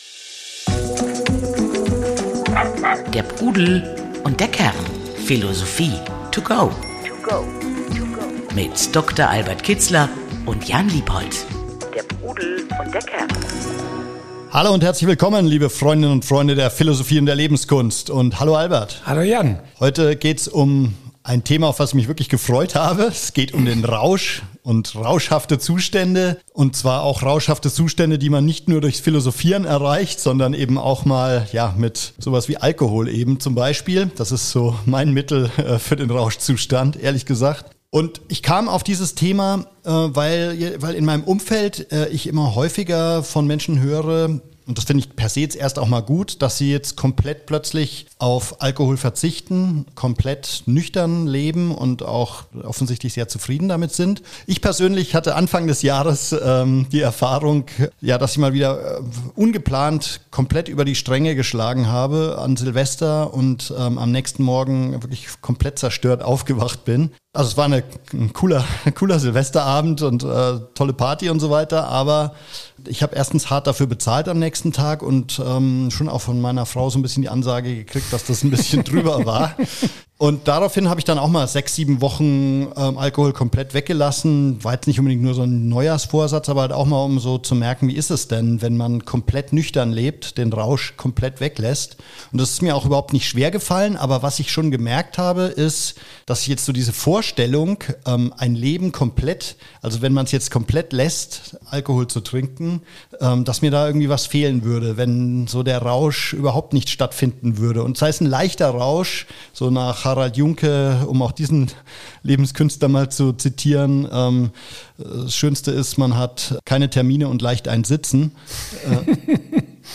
Das alles ist nicht als reiner Dialog geplant.